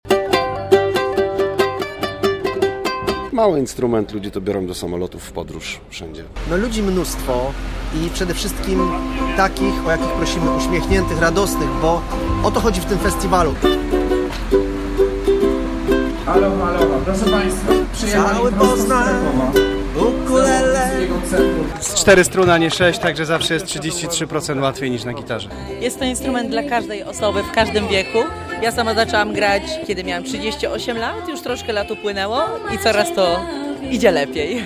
Setki osób uczestniczyły na placu Wolności w festiwalu Cały Poznań Ukulele.
- mówił uczestnik.
- dodawał kolejny.